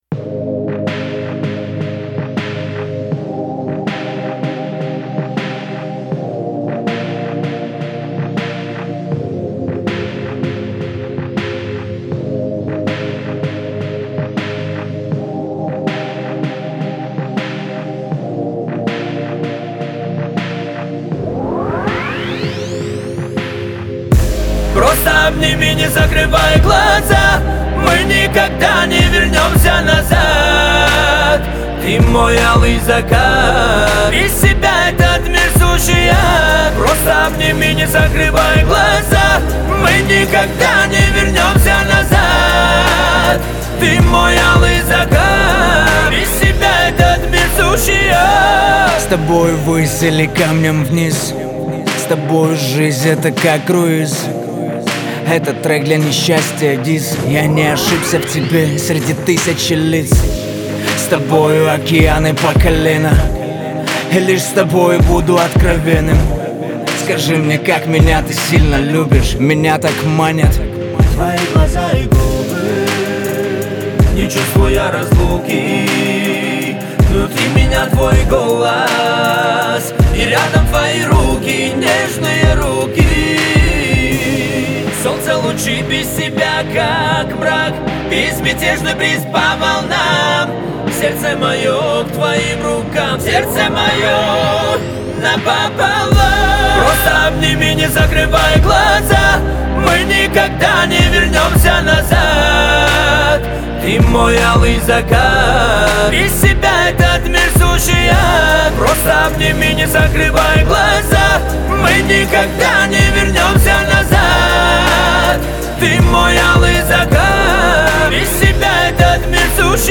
атмосферное произведение в жанре поп-рок